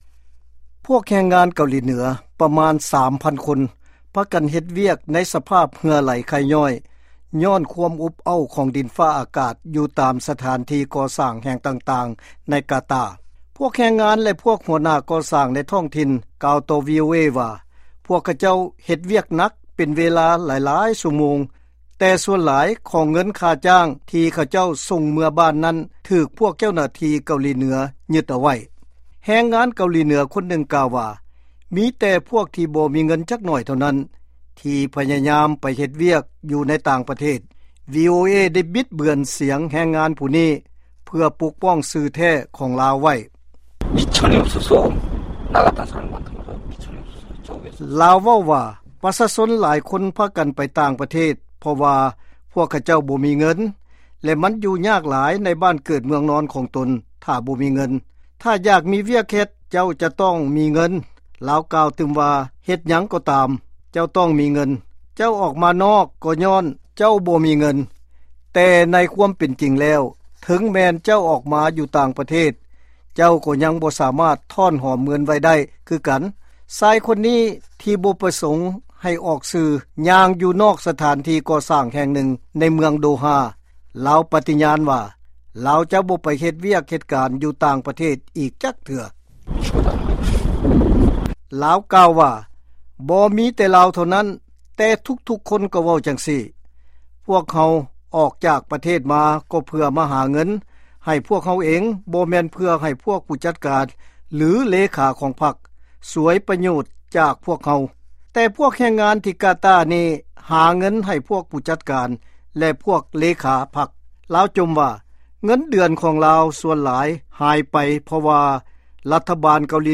ວີໂອເອໄດ້ບິດເບືອນສຽງແຮງງານຜູ້ນີ້ ເພື່ອປົກປ້ອງຊື່ແທ້ຂອງລາວໄວ້.